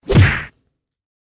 kick.ogg.mp3